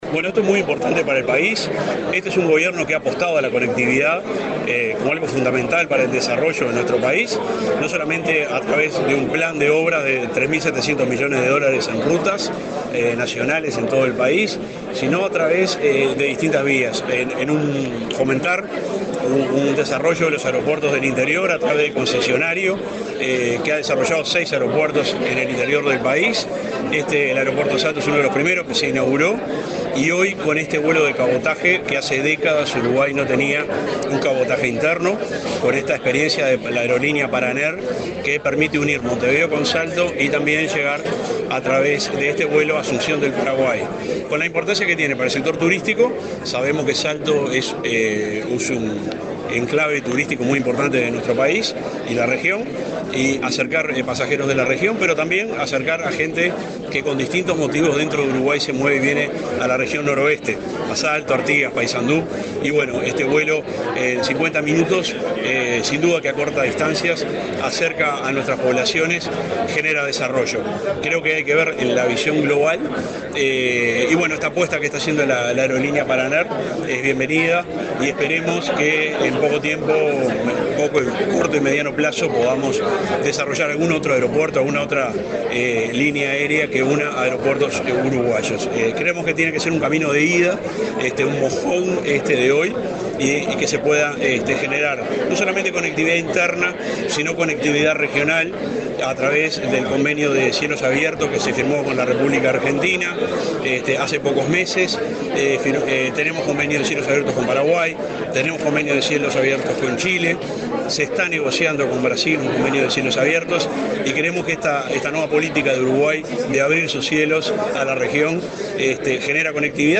Entrevista al subsecretario de Transporte, José Luis Olaizola